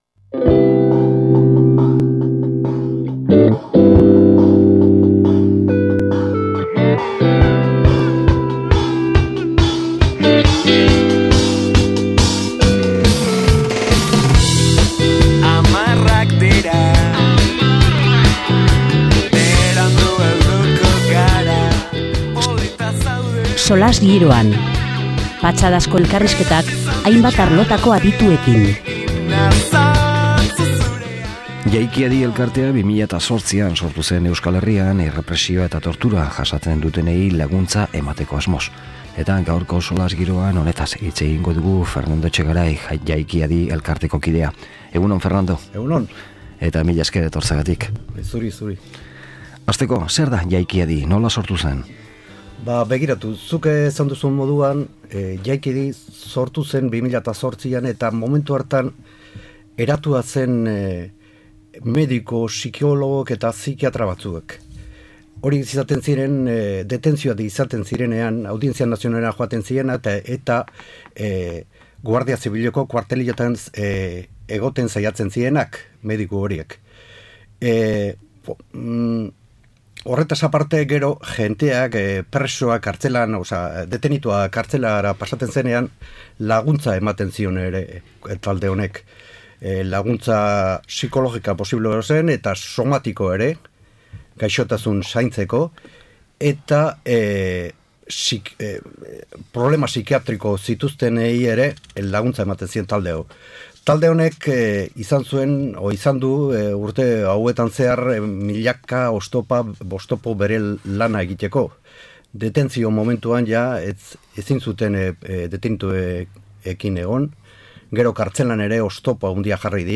Solasaldia